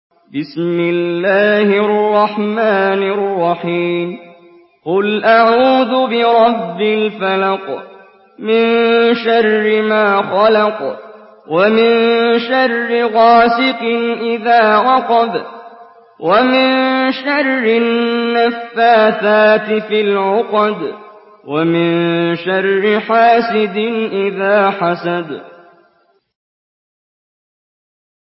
Surah আল-ফালাক্ব MP3 by Muhammad Jibreel in Hafs An Asim narration.
Murattal Hafs An Asim